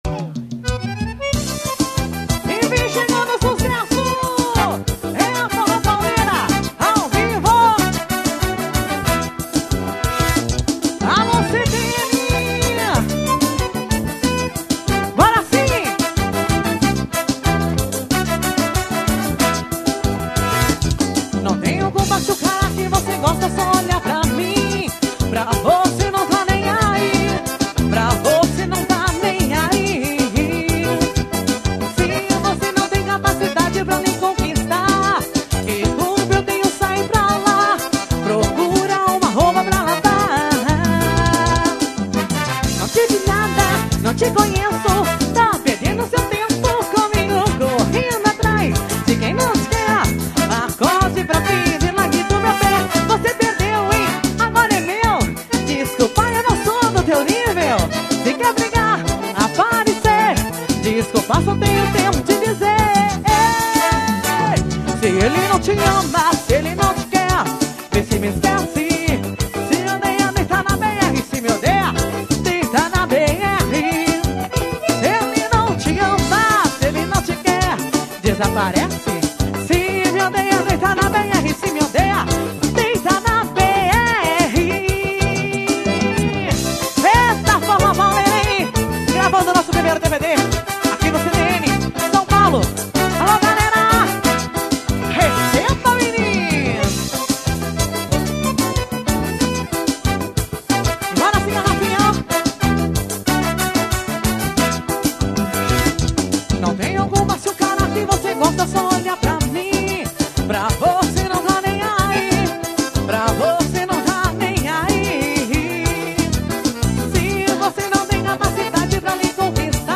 -GRAVADO AO VIVO NO CTN-LIMÃO.